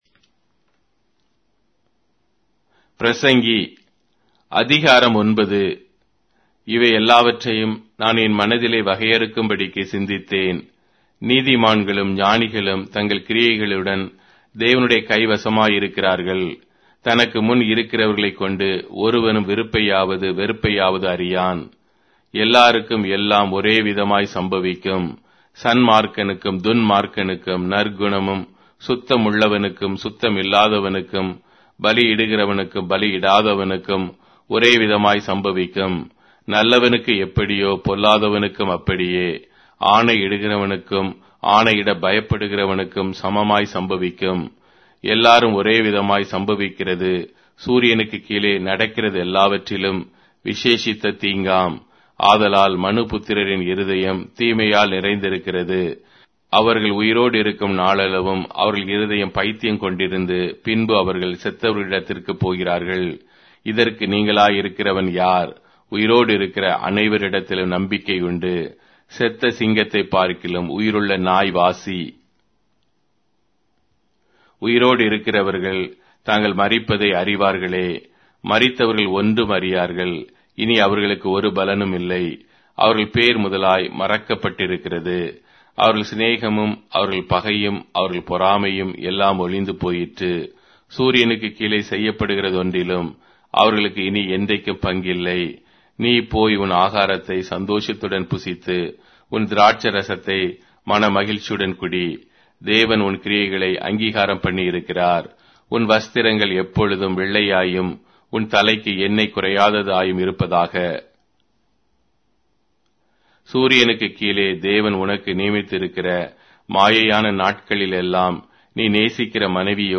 Tamil Audio Bible - Ecclesiastes 3 in Irvhi bible version